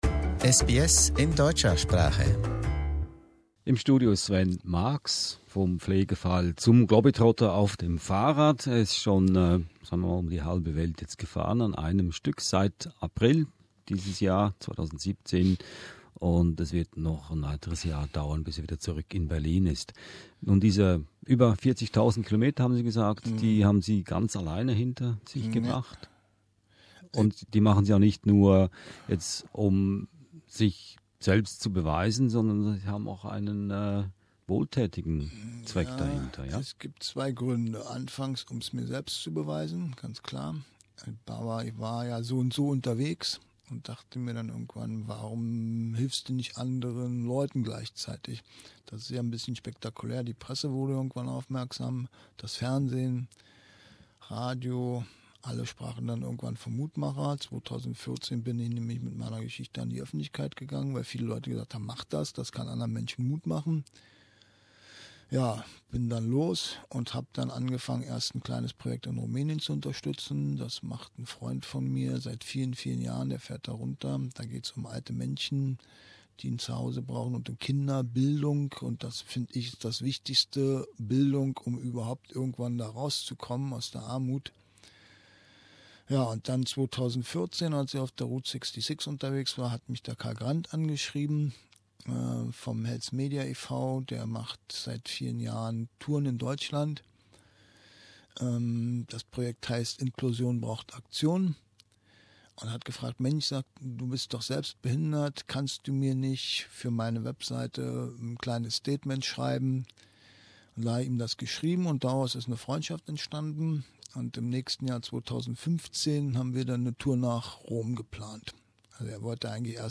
Er machte einen Zwischenhalt in den SBS Studios in Melbourne, um seine faszinierende Geschichte zu erzählen.